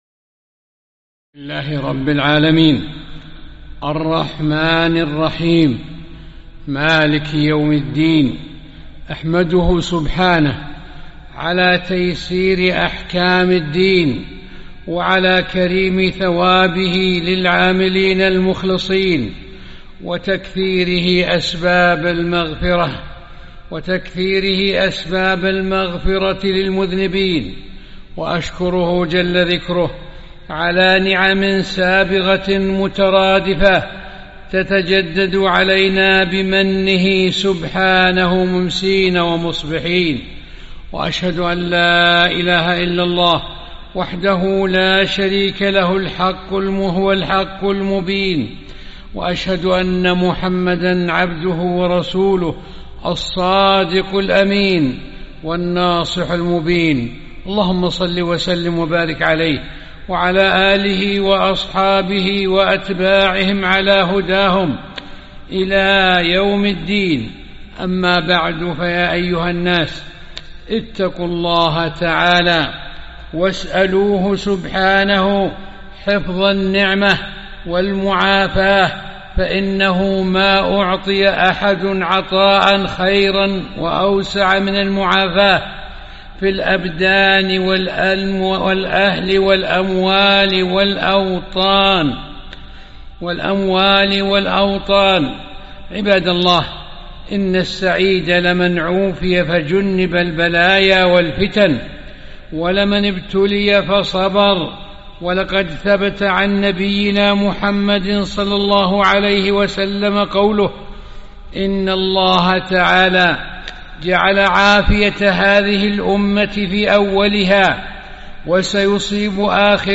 خطبة - وصايا للتعامل مع الفتن